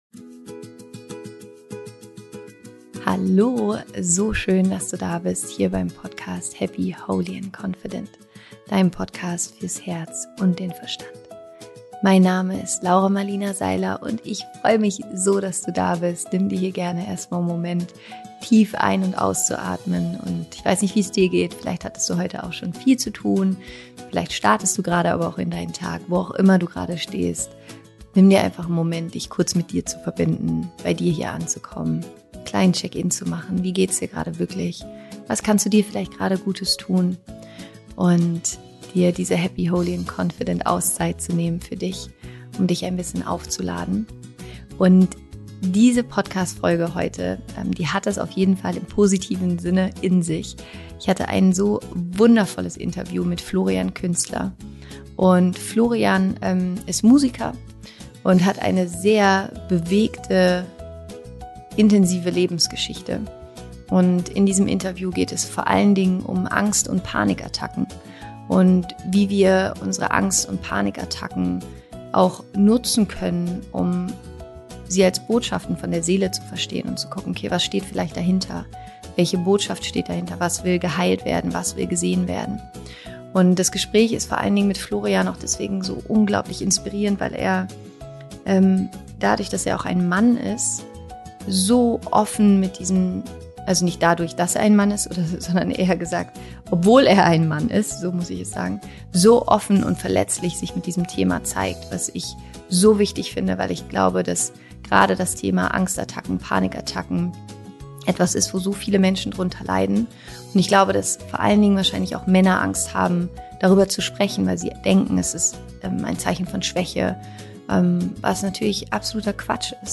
Angst- und Panikattacken als Botschaften der Seele - Florian Künstler im Interview